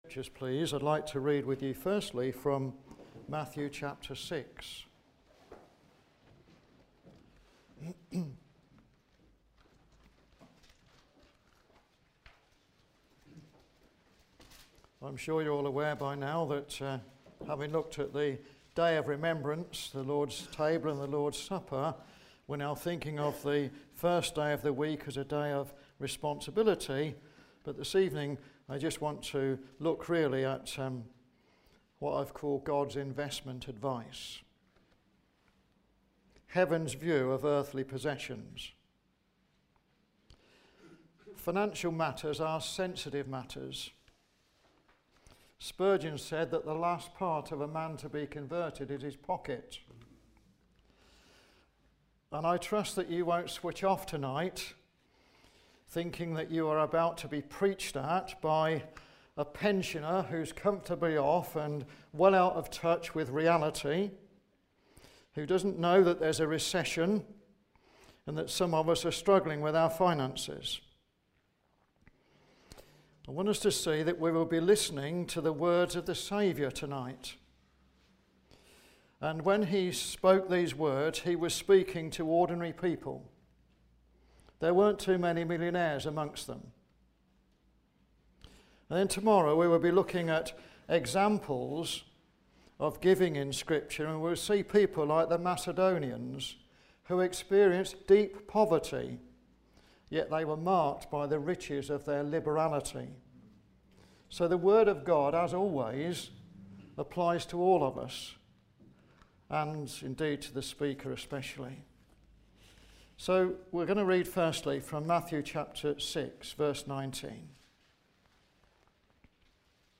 4:10-19 Service Type: Ministry